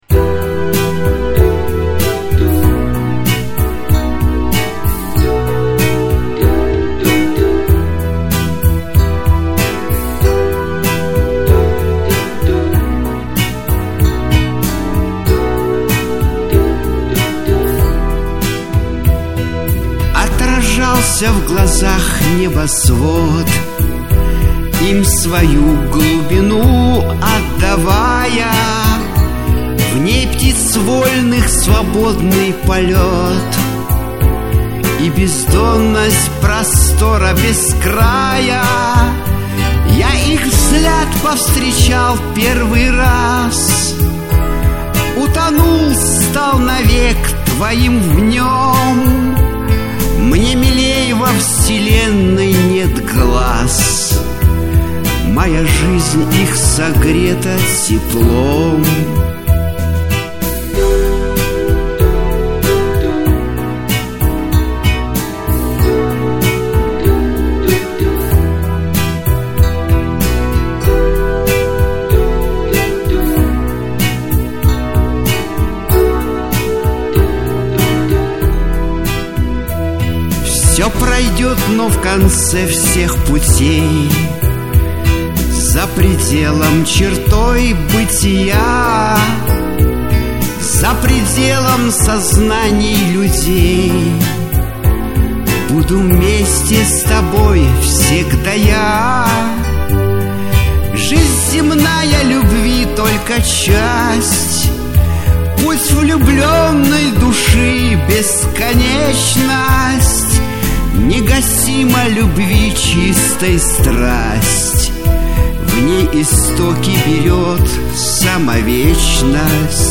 Лирическая музыка